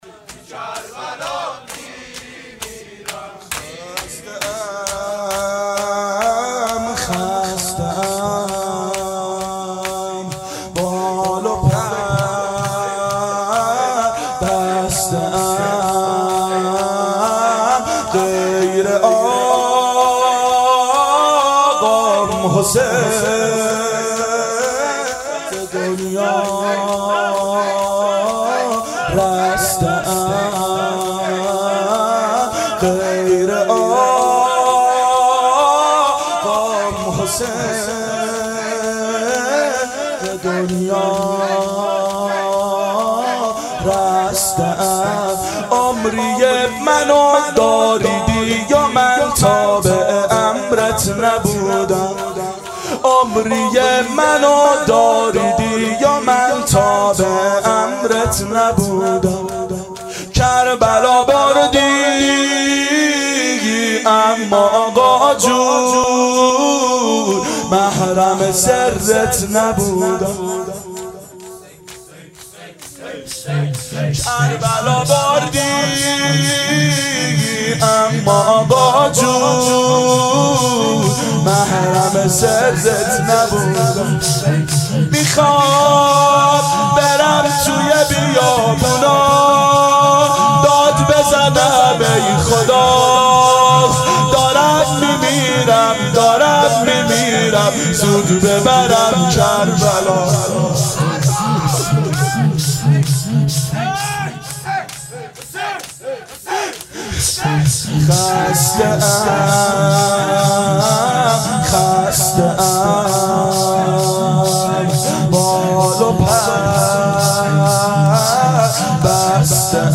دهه اول صفر سال 1390 هیئت شیفتگان حضرت رقیه س شب سوم (شام غریبان)